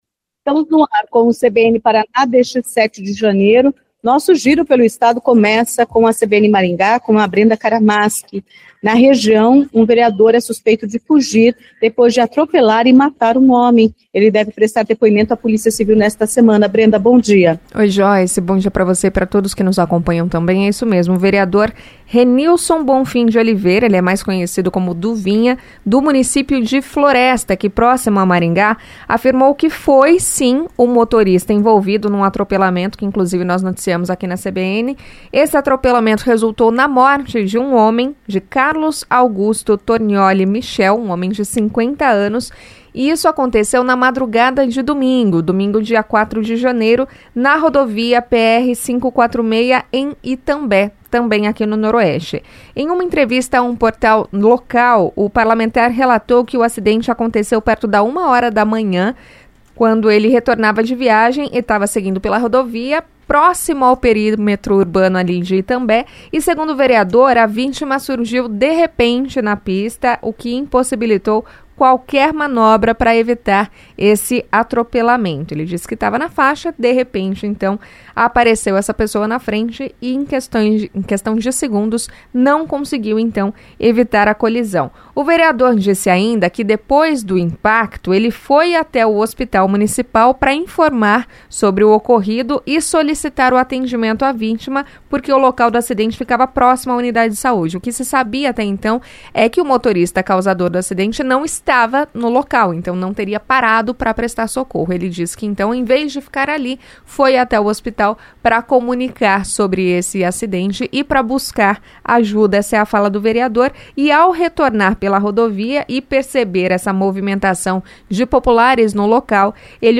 O CBN Paraná vai ao ar de segunda a sexta-feira, a partir das 10h35, com participação da CBN Maringá, CBN Curitiba, CBN Londrina, CBN Cascavel e CBN Ponta Grossa.